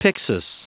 Prononciation du mot pyxis en anglais (fichier audio)
Prononciation du mot : pyxis